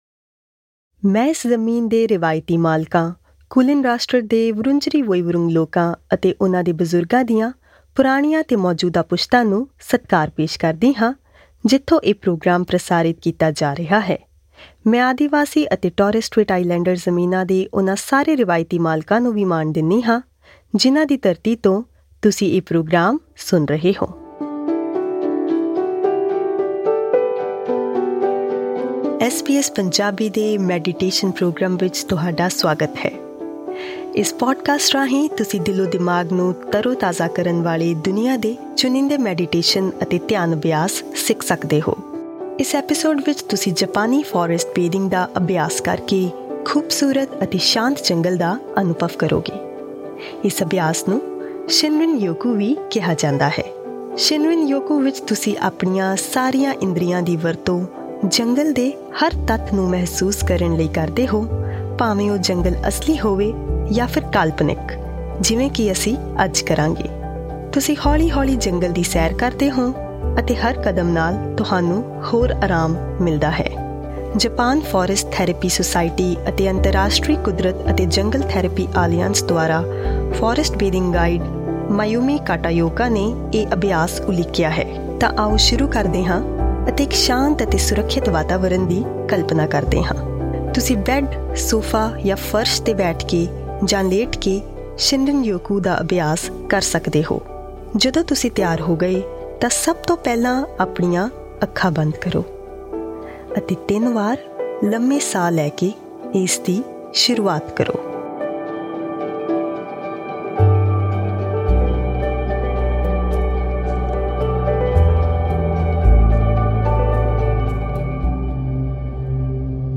ਸ਼ਿਨਰਿਨ-ਯੋਕੂ ਜਾਪਾਨੀ ਭਾਸ਼ਾ ਦਾ ਸ਼ਬਦ ਹੈ ਜਿਸਦਾ ਅਰਥ ਹੈ ‘ਫੌਰੈਸਟ ਬਾਥਿੰਗ’। ਇਸ ਮੈਡੀਟੇਸ਼ਨ ਅਭਿਆਸ ਵਿੱਚ ਅਸੀਂ ਇੱਕ ਸ਼ਾਂਤ ਅਤੇ ਸੁੰਦਰ ਜੰਗਲ ਦੀ ਸੈਰ ਕਰਾਂਗੇ।